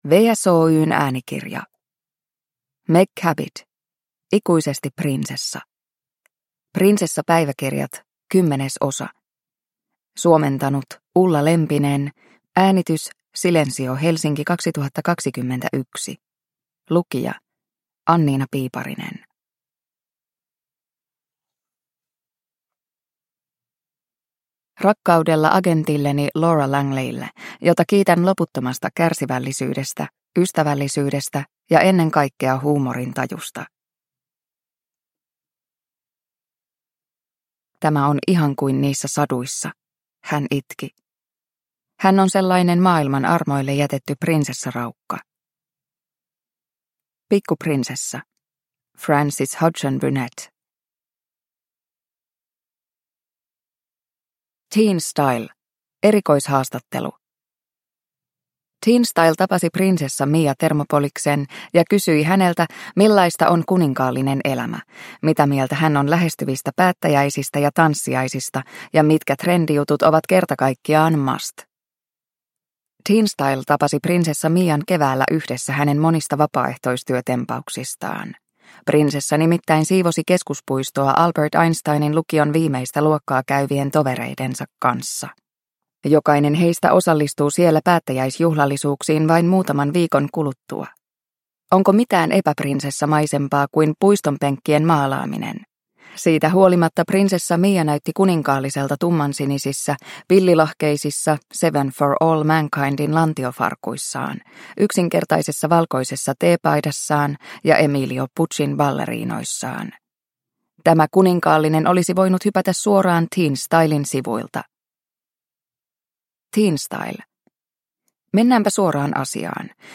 Ikuisesti prinsessa – Ljudbok